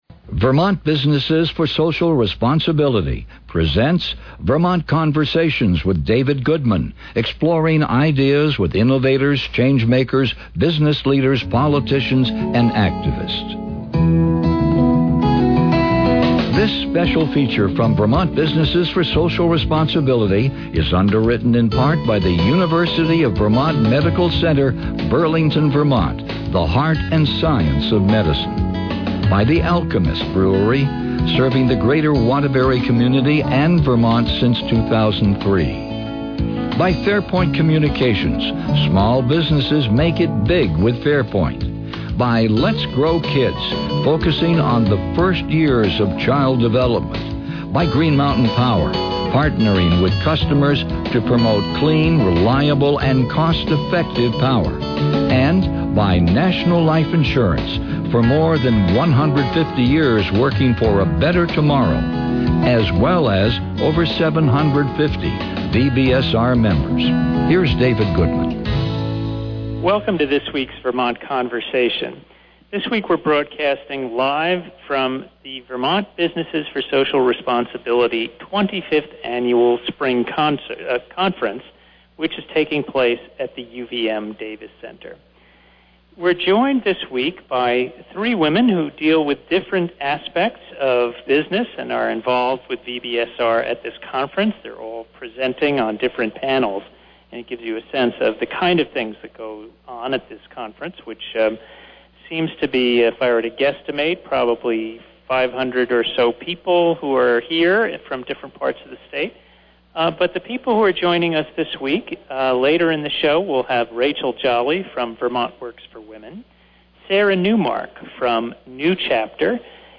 Live broadcast from the VBSR Spring Conference: we speak with three women with different perspectives on socially responsible business: